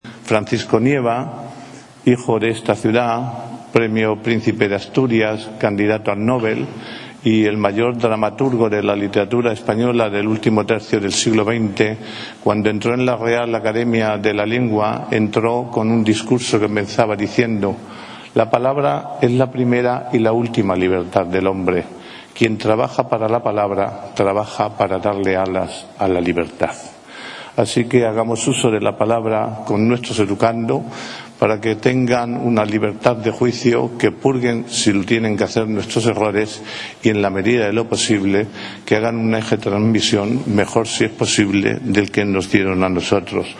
Valdepeñas ha acogido este viernes el acto institucional autonómico del Día de la Enseñanza 2024, que ha estado presidido por el jefe del Gobierno regional, Emiliano García-Page, y el alcalde de la localidad, Jesús Martín.
El alcalde de la localidad, Jesús Martín, ha remarcado en este día que la Educación “es una herramienta que se debe utilizar desde la independencia del intelecto para no cercenar la libertad y el entendimiento de juicio de nuestros hijos e hijas”.
cortealcalde_1.mp3